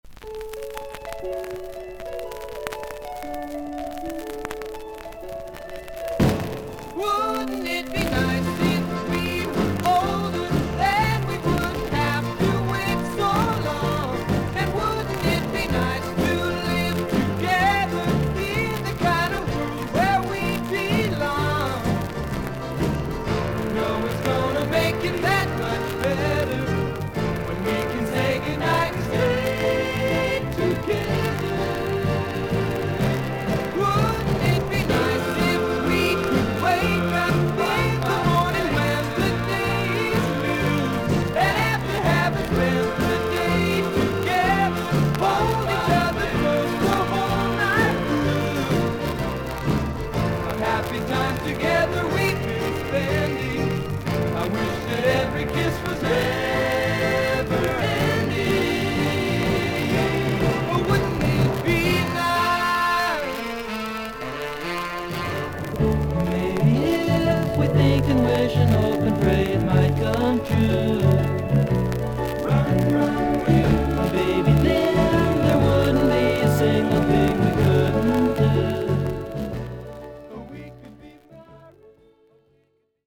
音のグレードはVG+〜VG:全体に大きめのサーフィス・ノイズあり。少々軽いパチノイズの箇所あり。
説明不要のロック名盤。
オリジナル・モノラル盤です。